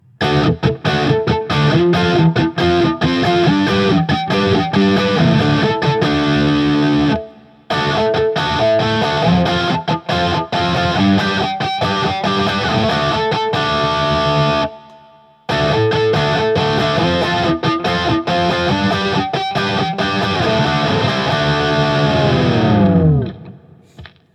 D-Shape Chords
As usual, for these recordings I used my normal Axe-FX Ultra setup through the QSC K12 speaker recorded into my trusty Olympus LS-10. As usual, I recorded using the Tiny Tweed patch, as well as the Backline patch, and then for fun used the Thor’s Hammer and Saw patch which is supposedly the Axe-FX’s take on a Diezel VH4 with a Rat overdrive in front (and some EQ and reverb on the back).